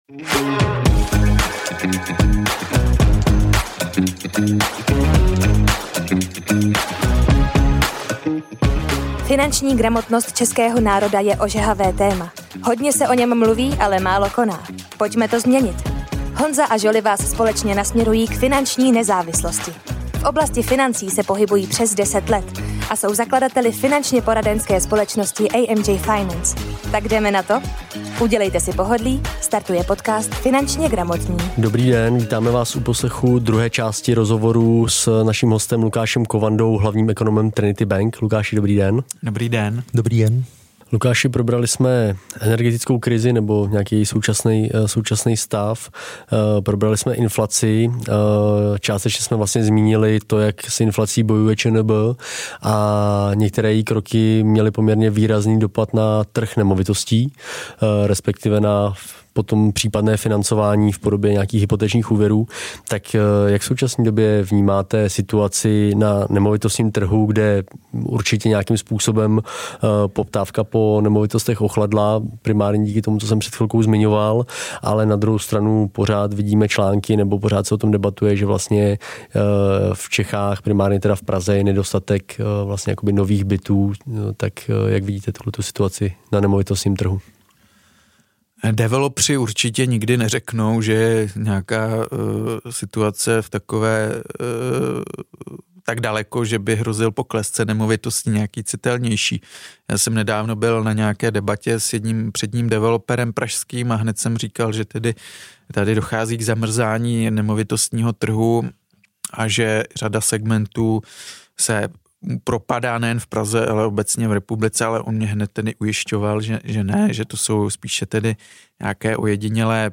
Ve druhém díle rozhovoru s ekonomem Lukášem Kovandou jsme řešili situaci na kapitálových trzích, probrali jsme také současný trh nemovitostí a nevynechali jsme ani kryptoměny a to jak Lukáš vidí jejich situaci do budoucna. Závěrem našeho rozhovoru jsme se bavili o akciích sportovních klubů. Nenechte si ujít tento zajímavý a inspirativní rozhovor s jedním z předních českých ekonomů.